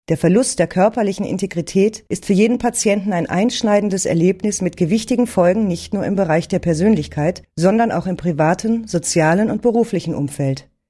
sprecherdemos
deutsch w_02